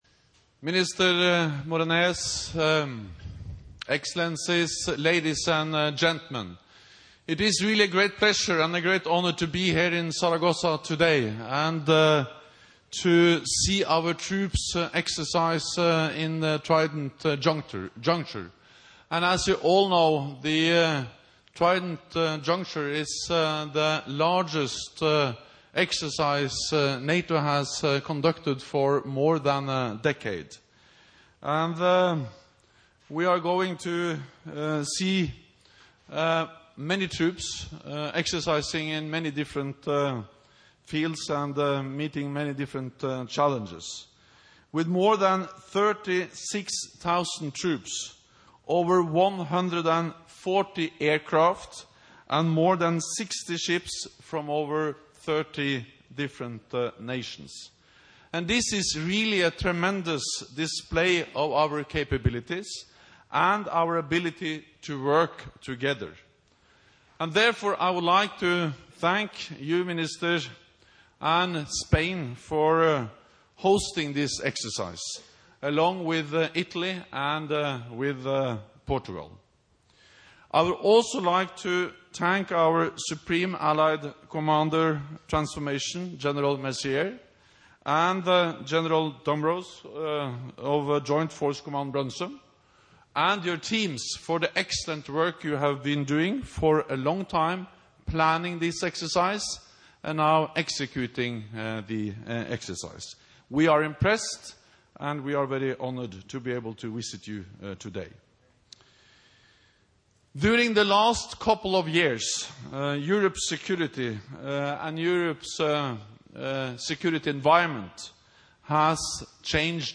Opening remarks by NATO Secretary General Jens Stoltenberg at the start of the Trident Juncture 2015 Distinguished Visitor's Day in Zaragoza, Spain